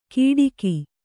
♪ kiṭiki